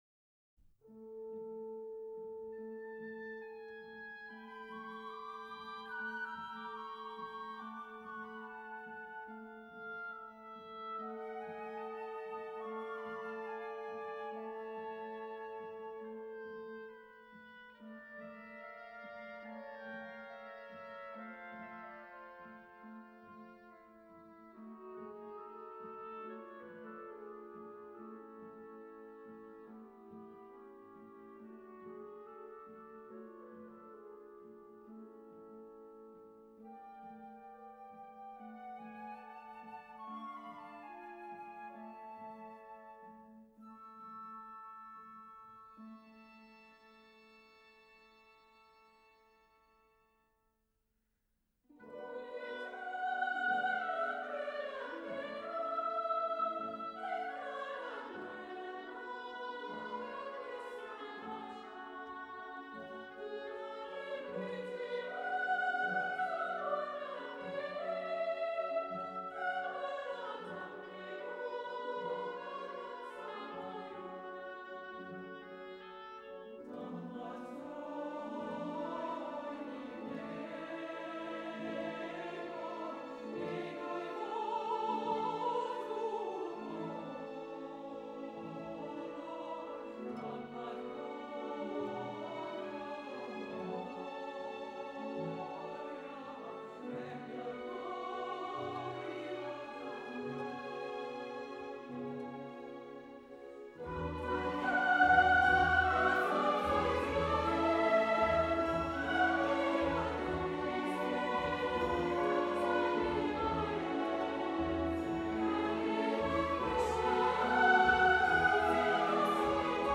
London Symphony Orcehstra and Chorus
Clásica